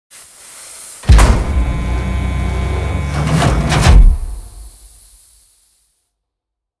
scrape2.ogg